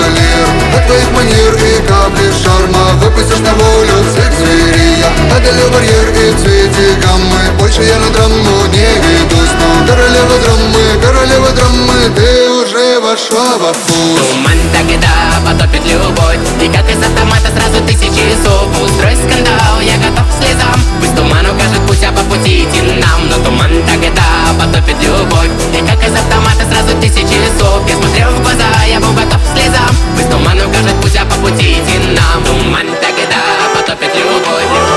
Жанр: Поп музыка / Танцевальные / Русский поп / Русские
Pop, Dance